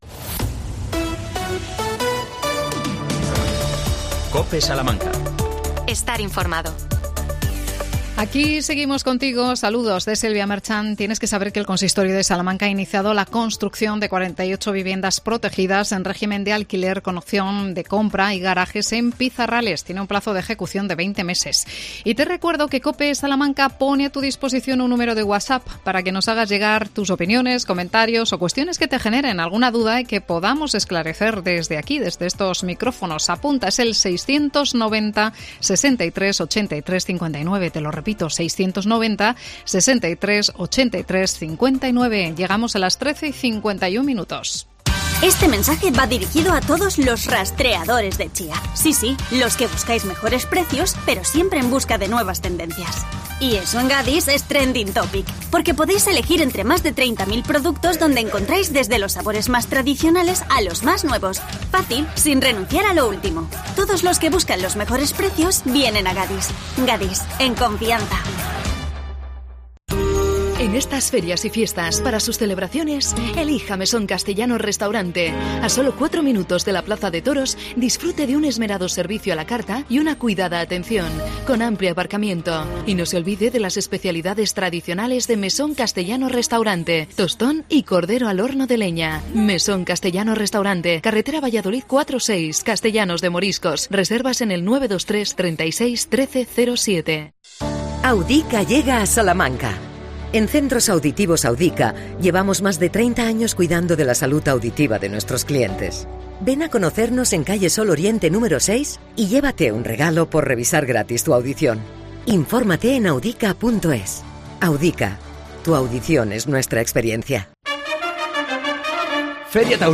¿Irá a la huelga la Asociación de Empresarios Salmantinos de Grúas de Auxilio en Carretera? Entrevistamos